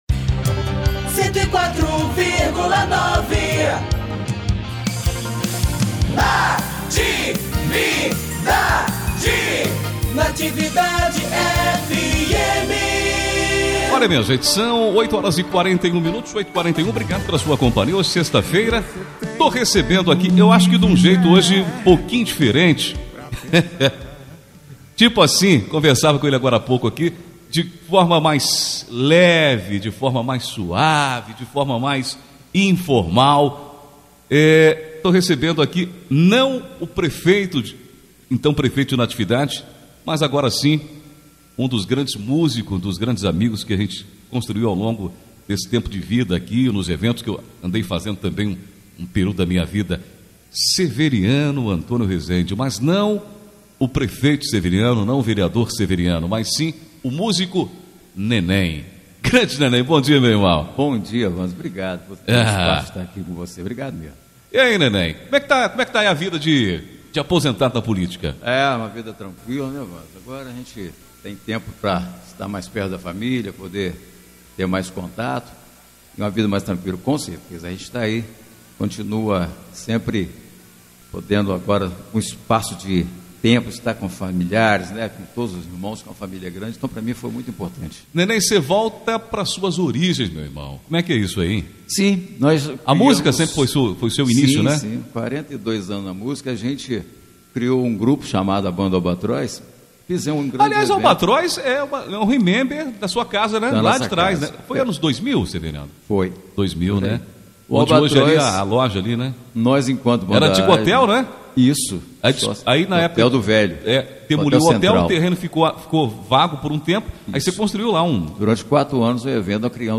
28 de novembro de 2025 DESTAQUE, ENTREVISTAS
Nesta sexta-feira (28), ele visitou a Rádio Natividade para divulgar um show especial na cidade.